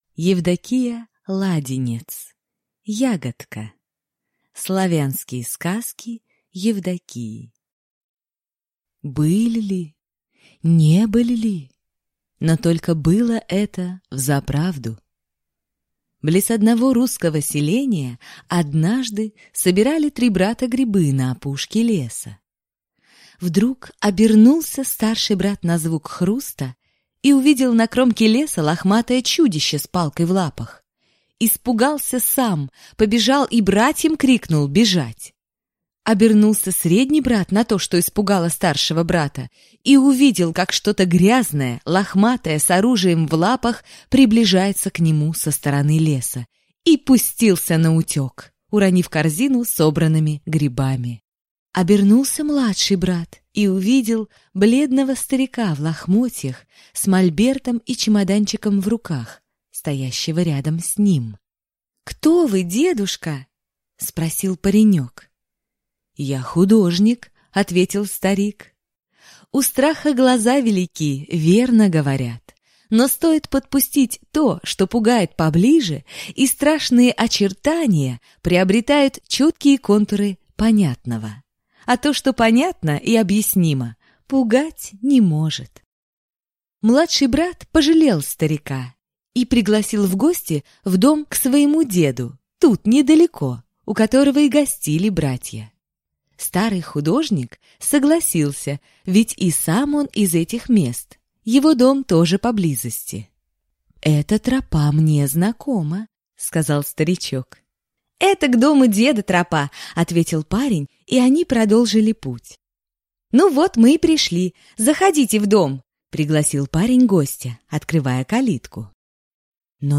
Аудиокнига Ягодка. Славянские сказки Евдокии | Библиотека аудиокниг